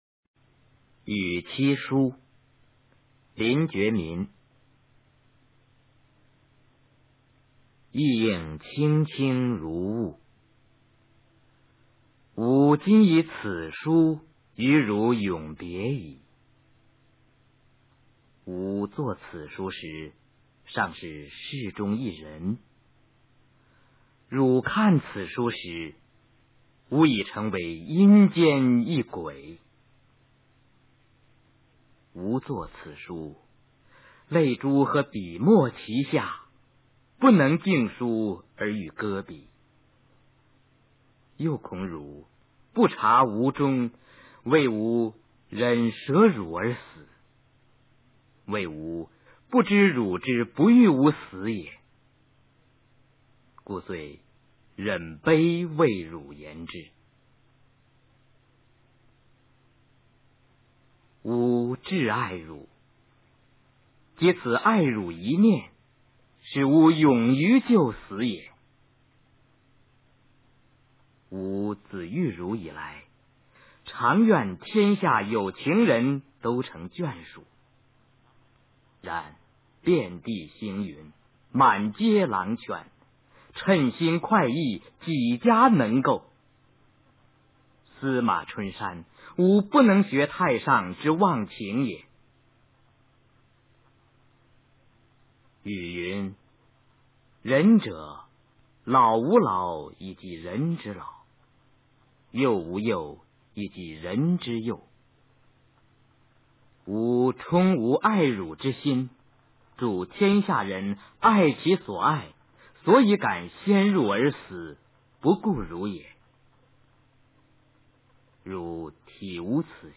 林觉民《与妻书》原文与译文（含朗读） 林觉民 语文教材文言诗文翻译与朗诵初中语文九年级下册 语文PLUS